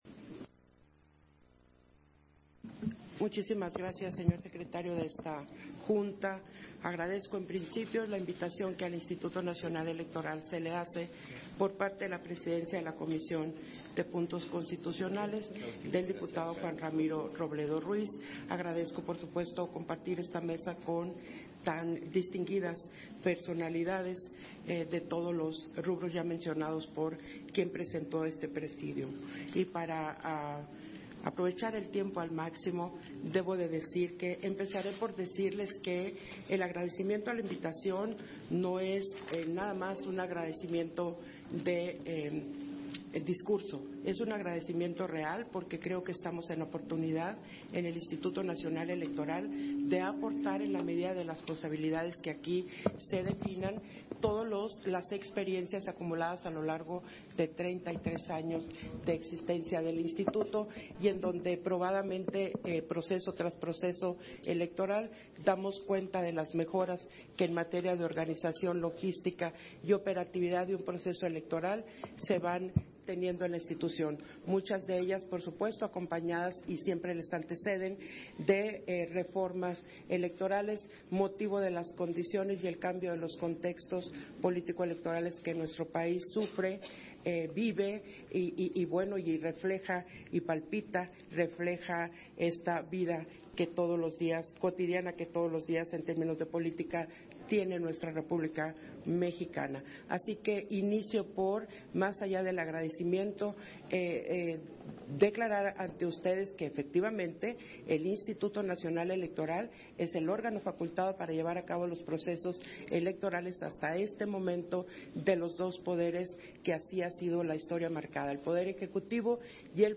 Intervención de la Consejera Presidenta del INE, Guadalupe Taddei, en los Diálogos Nacionales sobre la Reforma Constitucional al Poder Judicial